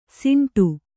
♪ siṇṭu